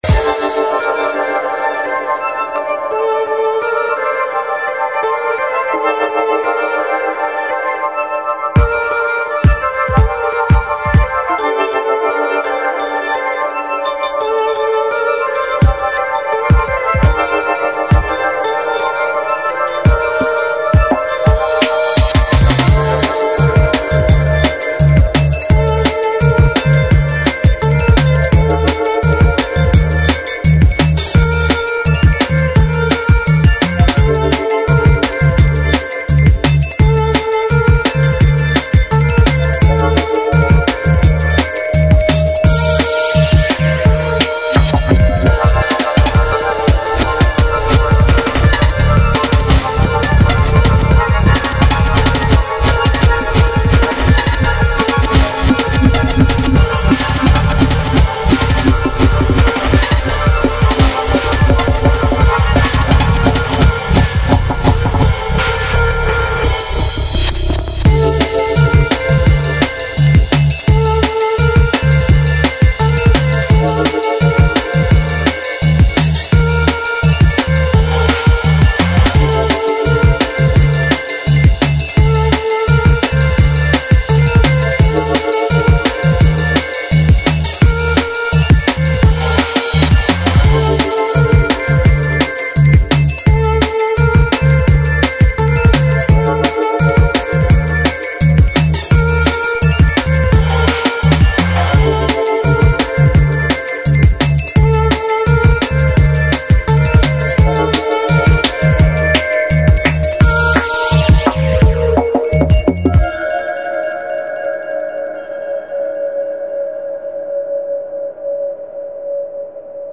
Artcore